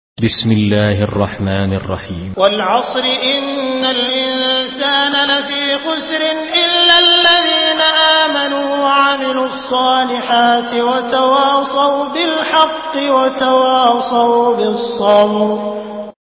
Surah আল-‘আসর MP3 in the Voice of Abdul Rahman Al Sudais in Hafs Narration
Surah আল-‘আসর MP3 by Abdul Rahman Al Sudais in Hafs An Asim narration.
Murattal Hafs An Asim